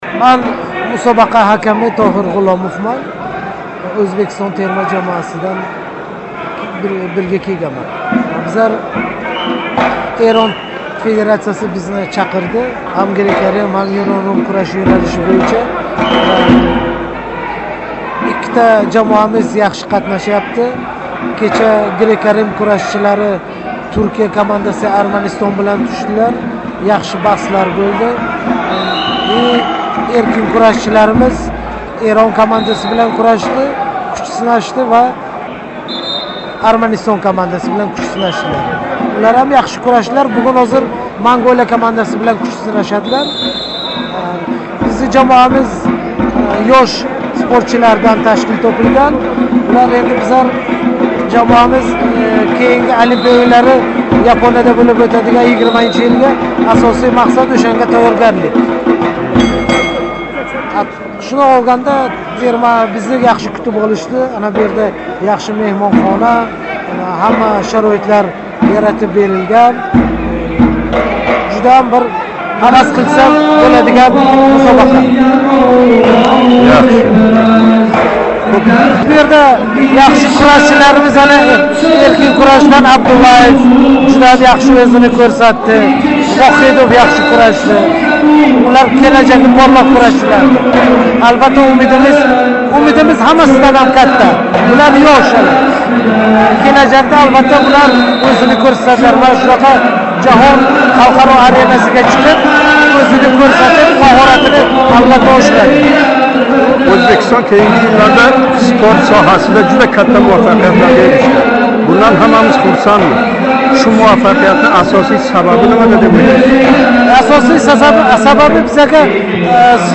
суҳбатини